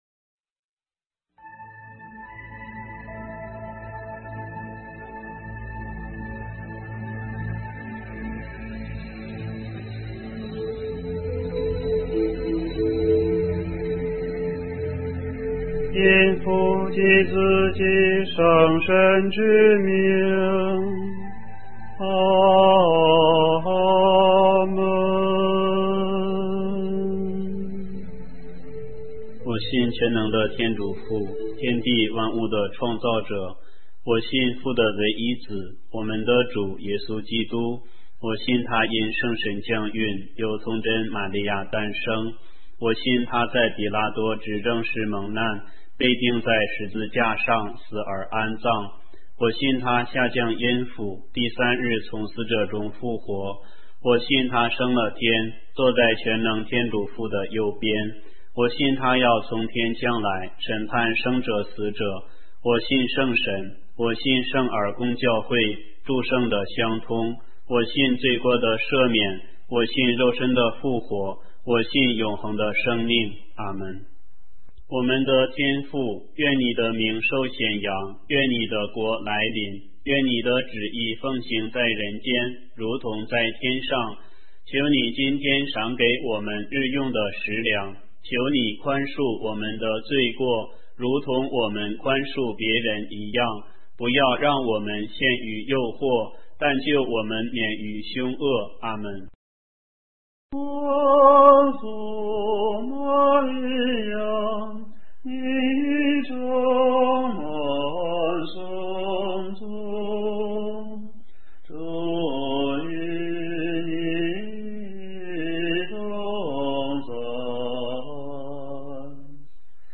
音频/有声阅读/有声祈祷/玫瑰经 • 在线资料库
光明五端(歌唱版).mp3